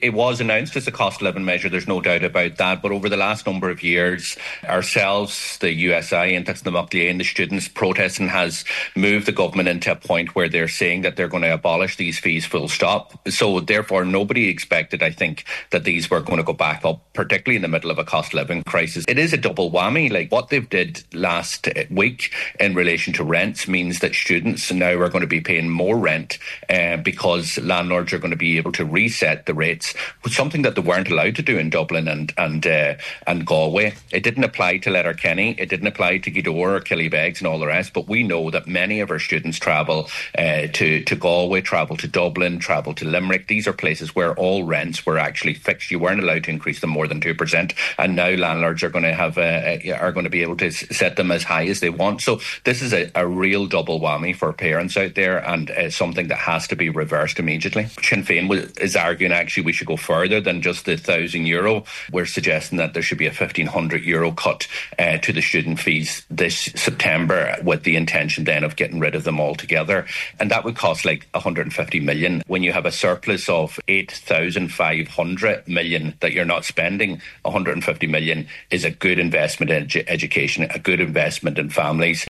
On today’s Nine til Noon Show, Deputy Pearse Doherty said rather than reversing the decrease, the minister should be using budgetary surpluses to follow through on a pledge to scrap the fees completely…………..